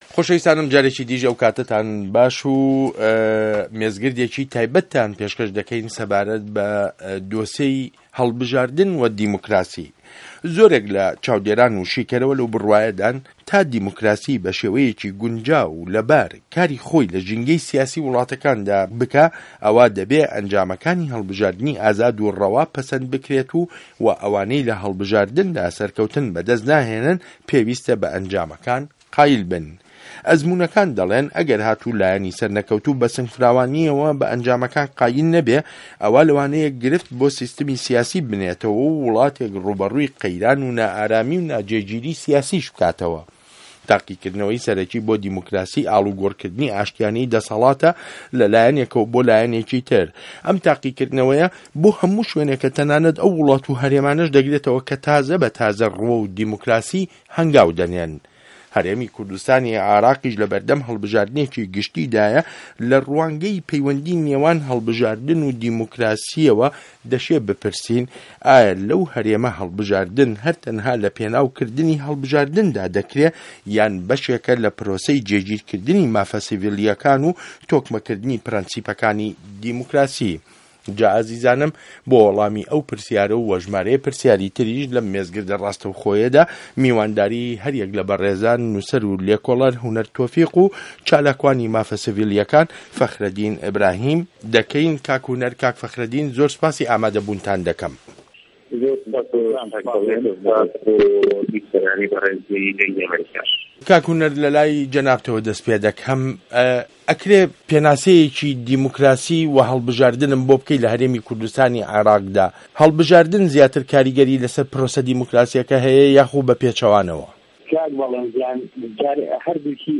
مێزگرد : هه‌ڵبژاردن و دیموکراسی له‌ هه‌رێمی کوردستانی عێراق